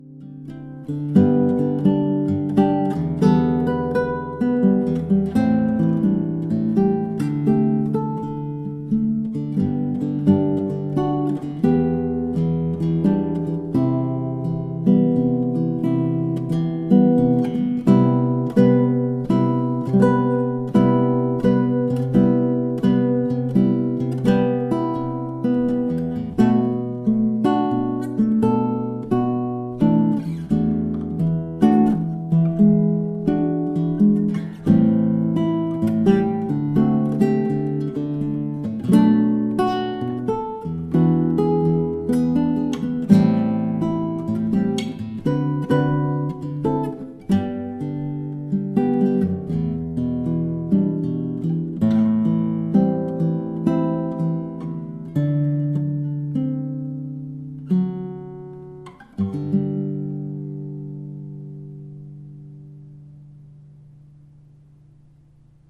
classical guitar solo